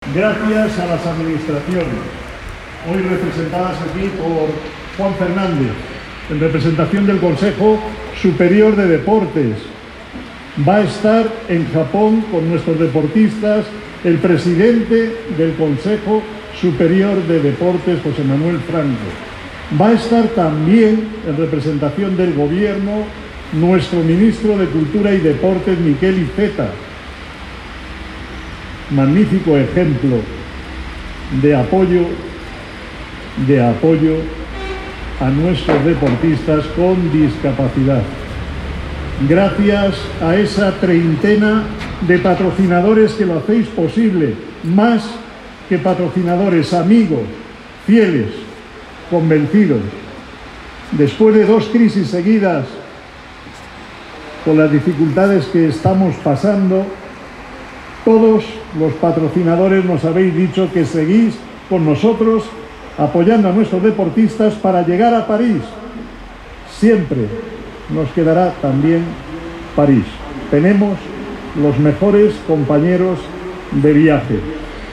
manifestó Carballeda formato MP3 audio(0,48 MB) en la inauguración de la Casa Paralímpica, ante el embajador de Japón en España, Kenji Hiramatsu, representantes de empresas patrocinadoras del equipo paralímpico, miembros del CPE, medios de comunicación y responsables de la administración madrileña y del gobierno de nuestro país,